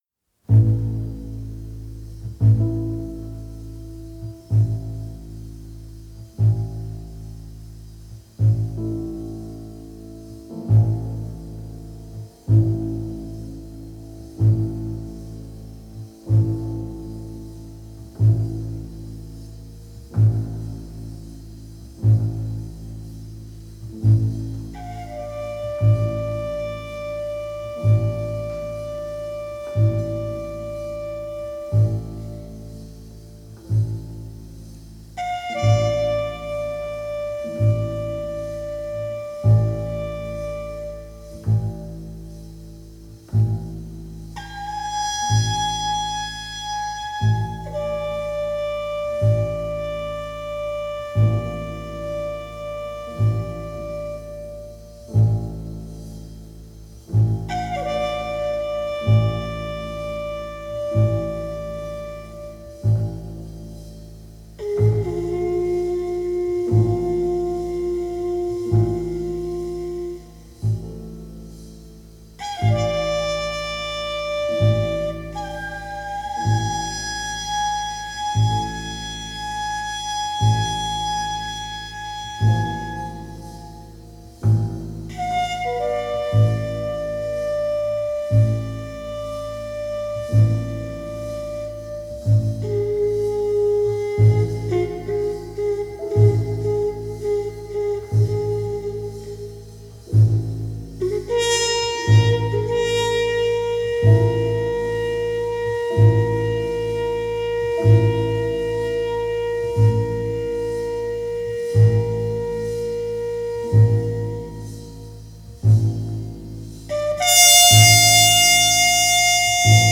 • Жанр: Джаз